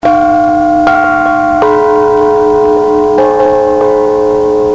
The 5 dot windbell (19") has very deep, rich and mellow tones. The sound of this windbell is comparable to three large church bells.